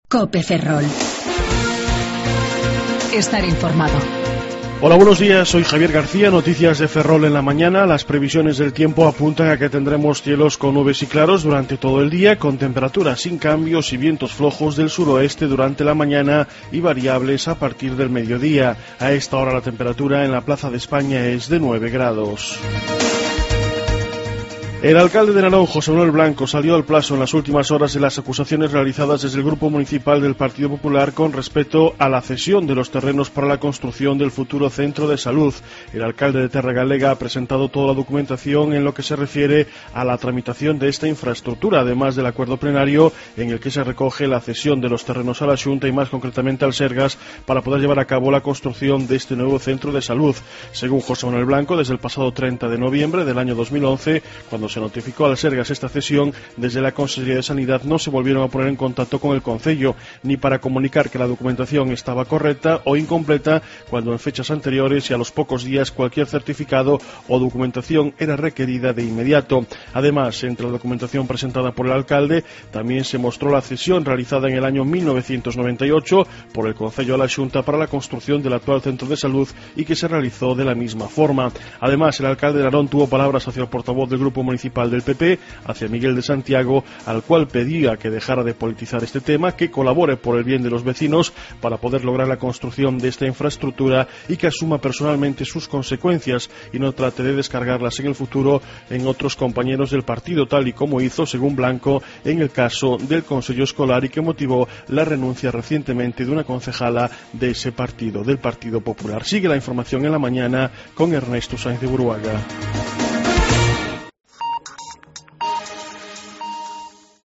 07:28 Informativo La Mañana